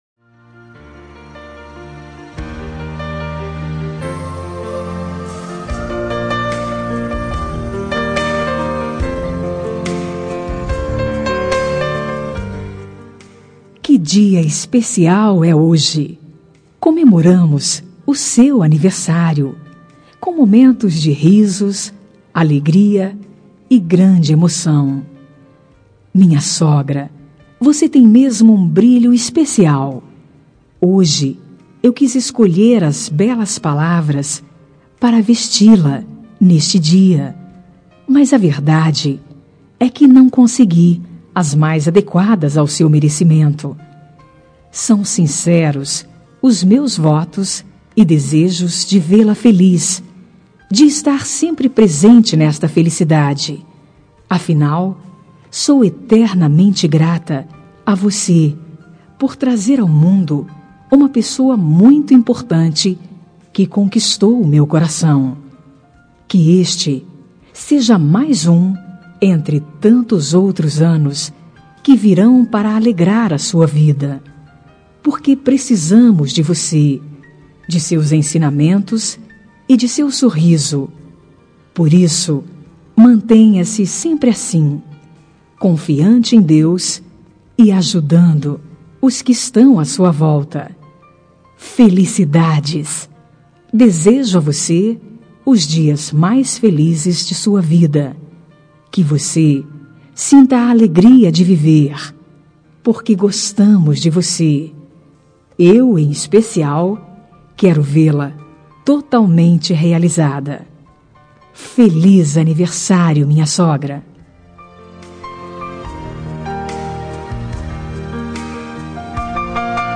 Telemensagem de Aniversário de Sogra – Voz feminina – Cód: 1966
7N 34 Instrumental.mp3